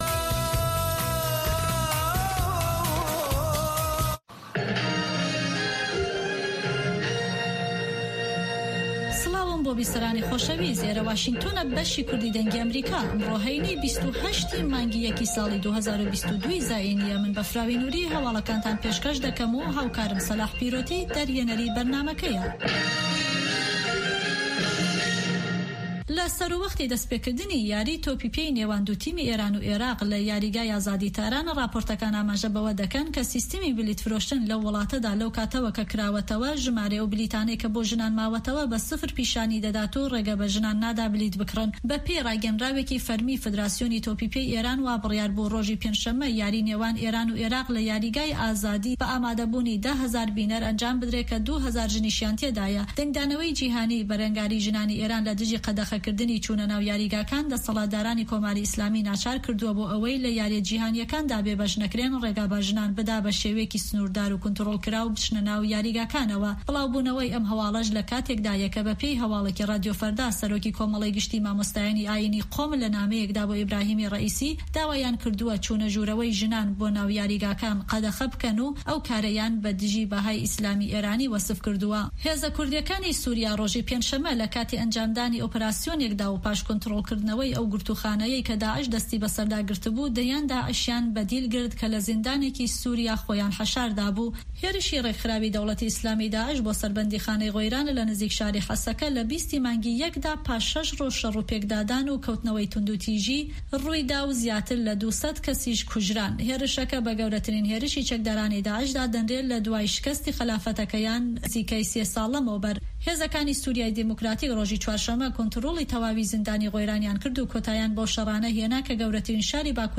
هەواڵەکانی 1 ی شەو